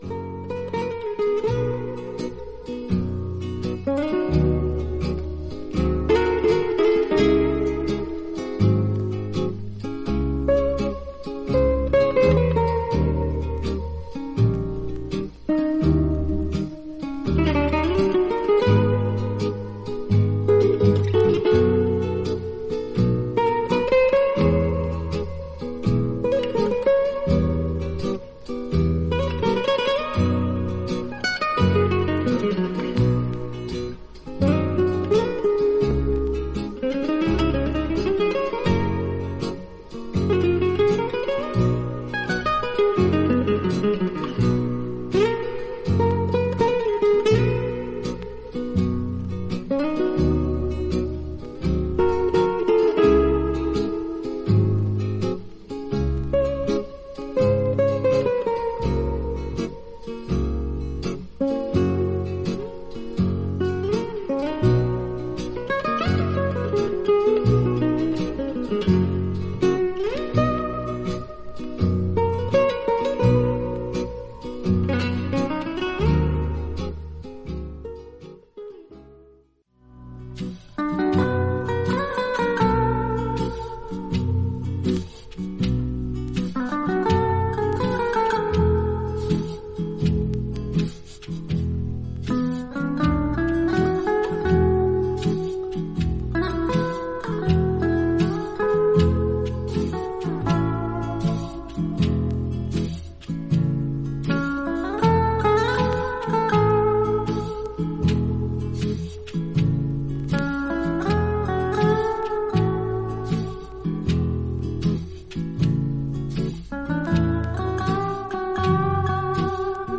ハワイアンにも通ずる、ゆったりとレイドバックしたグルーヴが心地良い